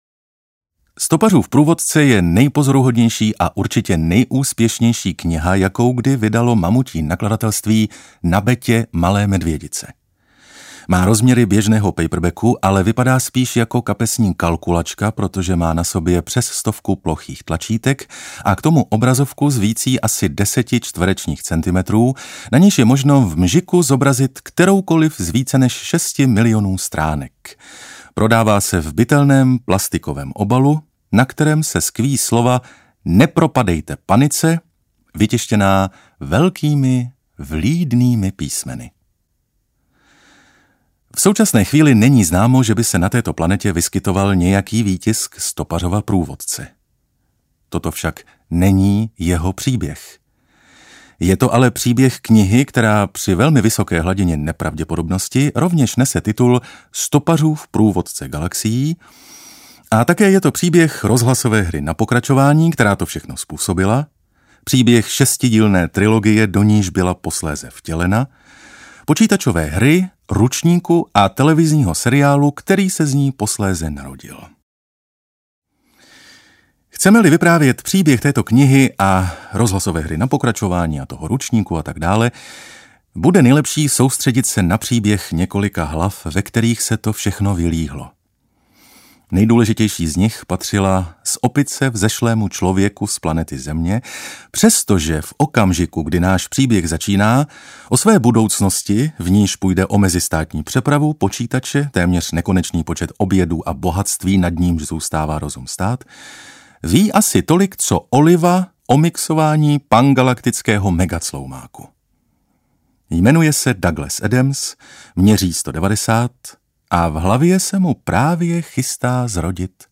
Audiokniha Nepropadejte panice – Douglas Adams a Stopařův průvodce po Galaxii, kterou napsal Neil Gaiman.
Ukázka z knihy